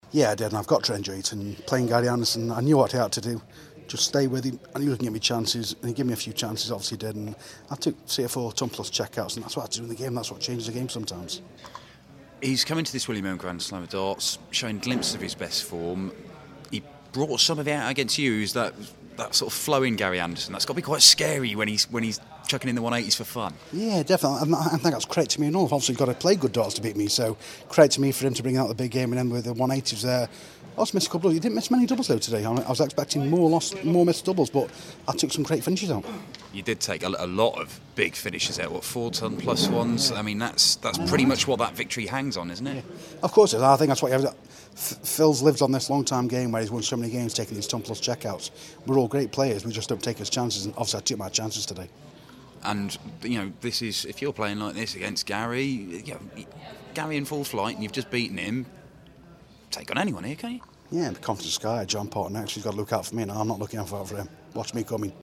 William Hill GSOD - Hamilton Interview (Last 16)